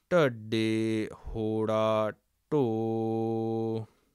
muharni - A Clojure library designed to hack up a web page for studying the Punjabi script and the pronunciation thereof approved for the reading of Sikh sacred texts.